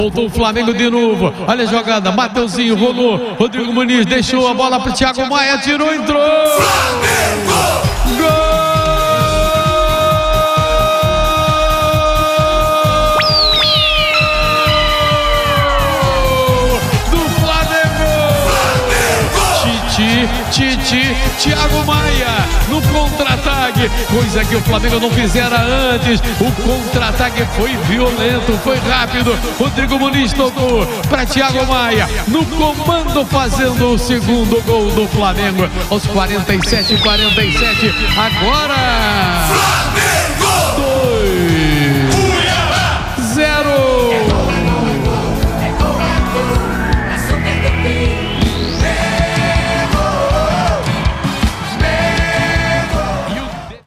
Ouça os gols da vitória do Flamengo sobre o Cuiabá com a narração de José Carlos Araújo